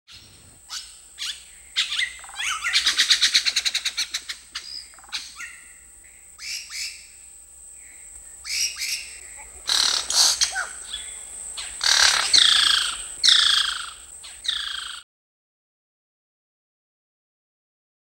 animal
Monkey Chatter Squawks